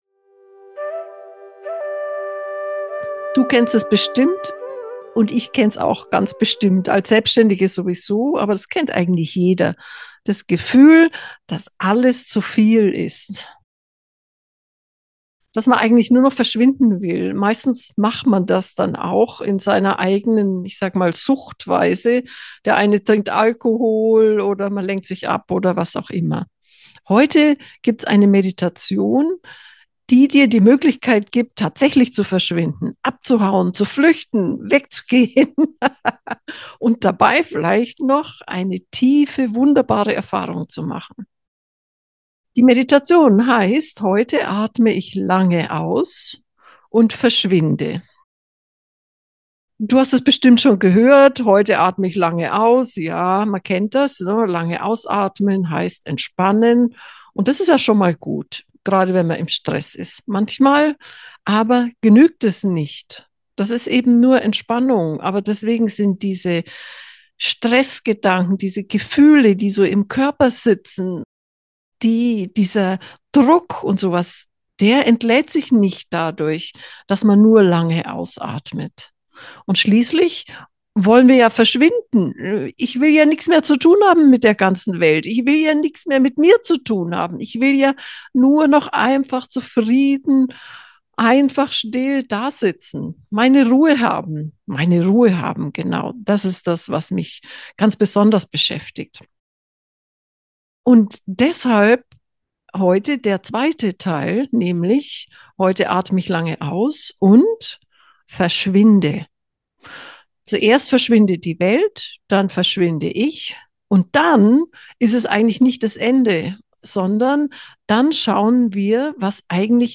Geführte Meditationen
ausatmen-verschwinden-meditation.mp3